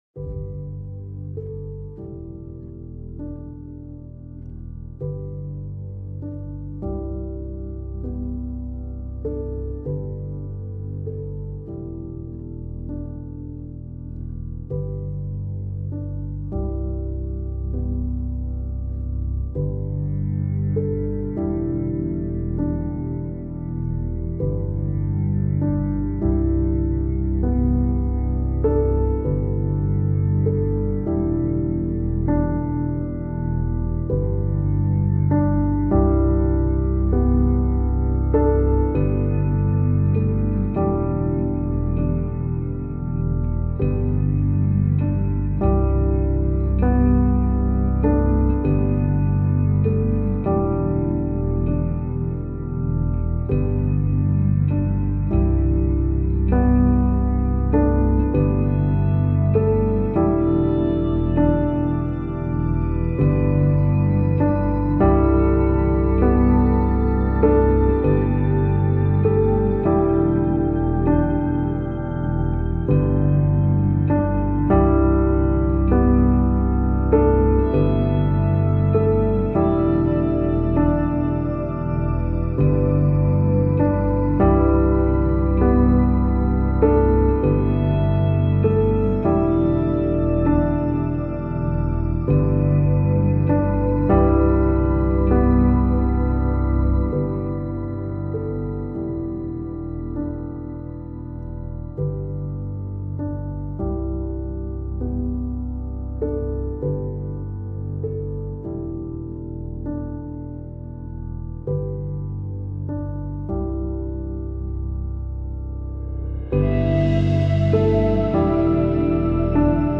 پیانو
موسیقی بی کلام آرامش بخش پیانو تخیلی و رویایی